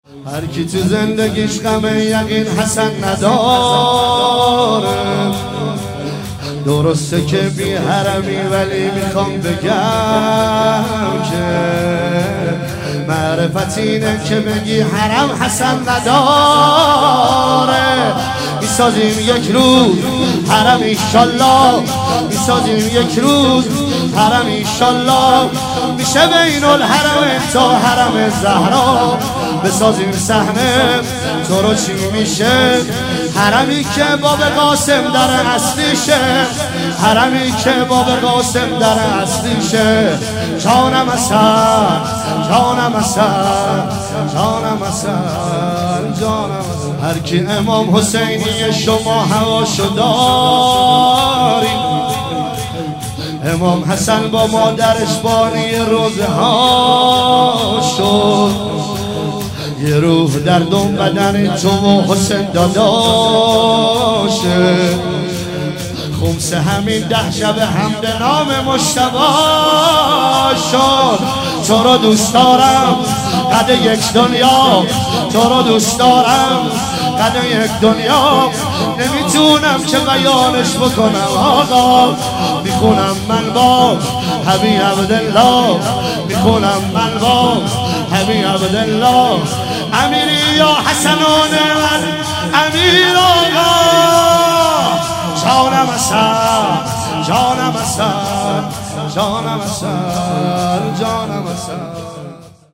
عنوان ظهر شهادت امام حسن مجتبی علیه السلام مشهد مقدس ۱۳۹۸